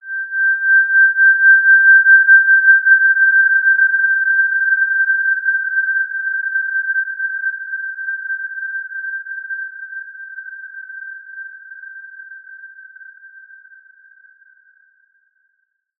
Basic-Tone-G6-mf.wav